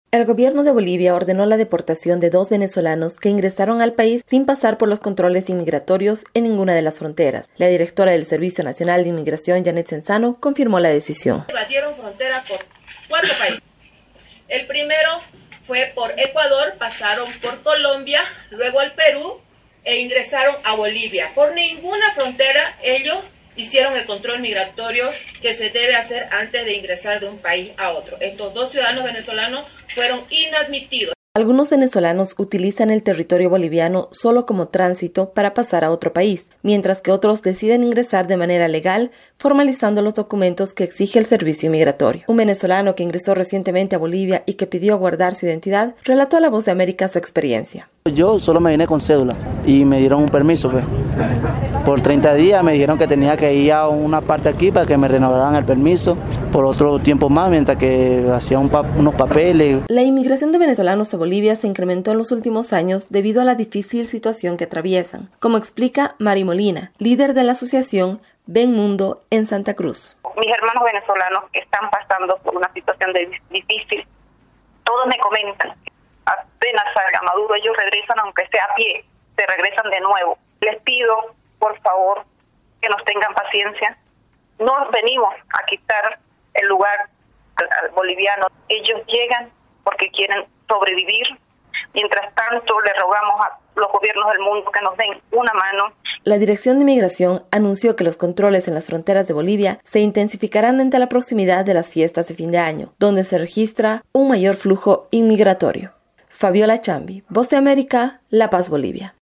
VOA: Informe desde Bolivia